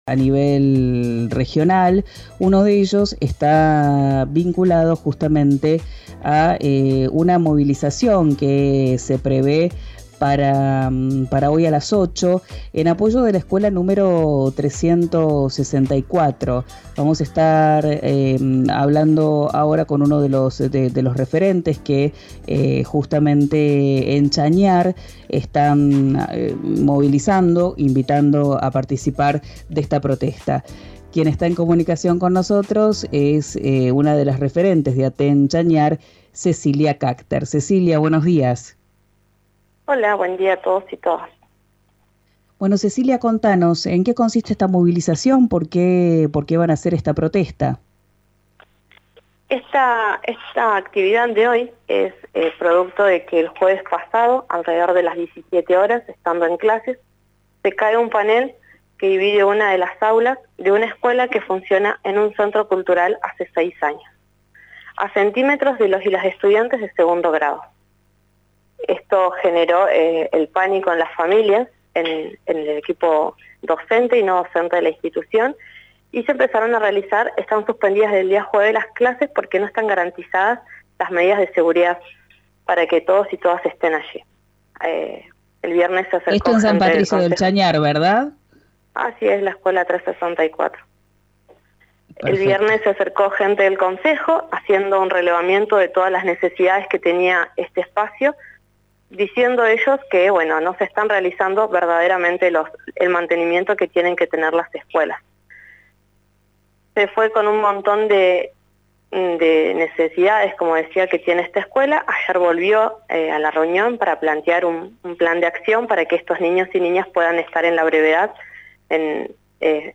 en RÍO NEGRO RADIO